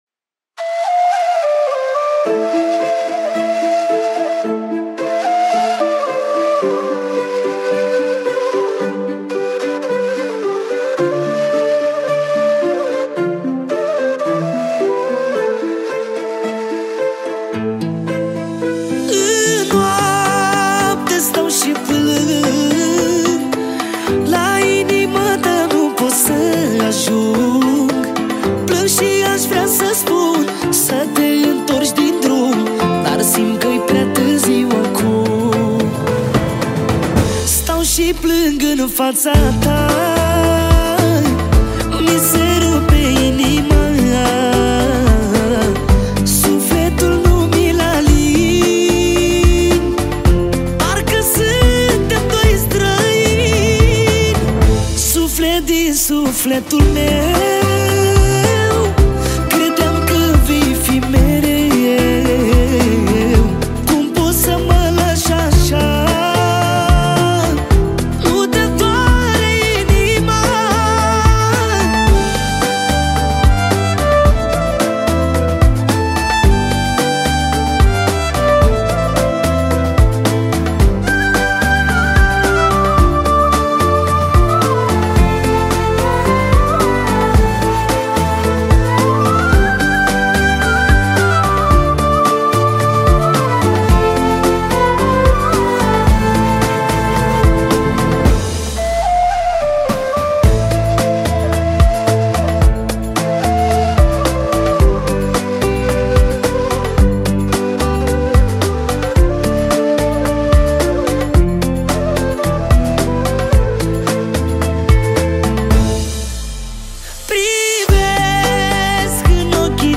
Data: 11.10.2024  Manele New-Live Hits: 0